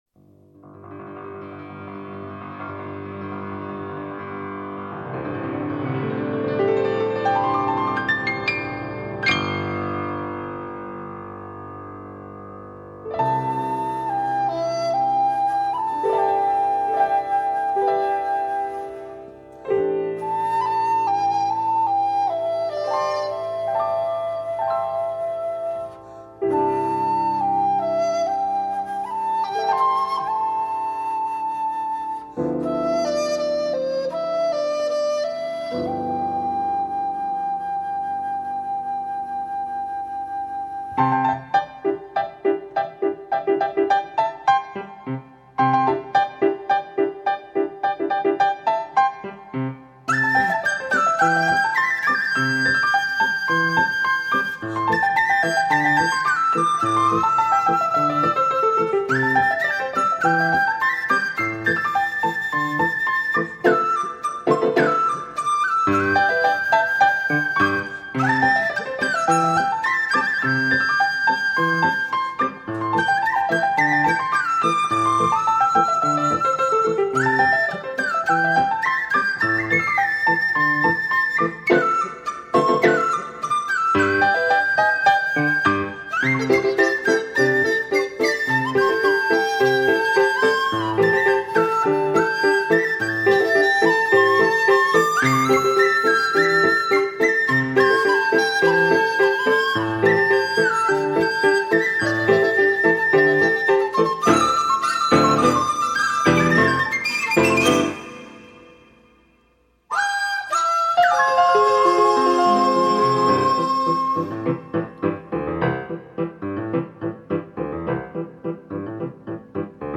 钢琴演奏
浓厚的中国古典风味